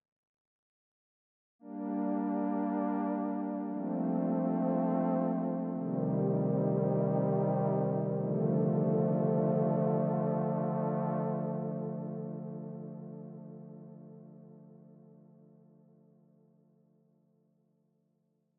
The digitone keys factory sound bank has a Juno-inspired preset called JANOPAD FP.
Open up the envelopes a little to get something like this—